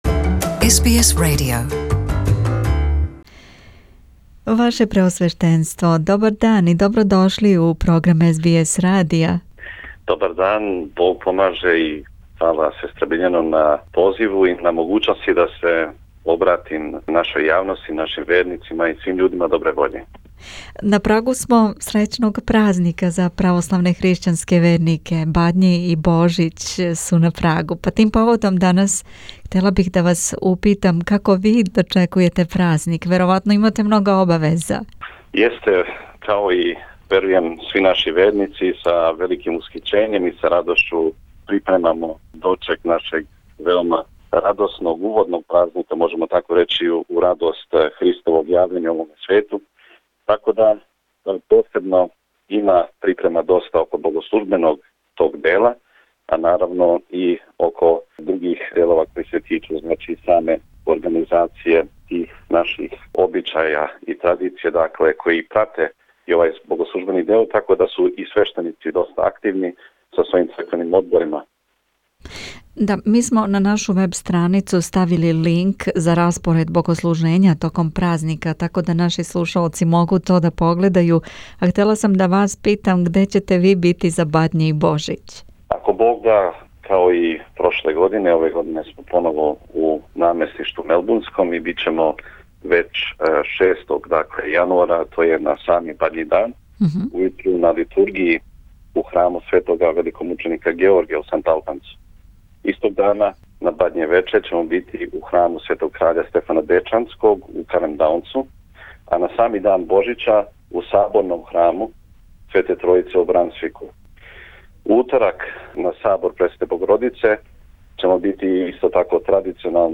Епископ Митрополије аустралијско-новозеландске, његово преосвештенство г. Силуан ракао нам је више о припемама за Бадње и Божић у српским црквама широм Аустралије. Он такође шаље поруке љубави свим слушаоцима. Такође је укратко сумирао 2018. годину у оквиру црквених активности у Аустралији.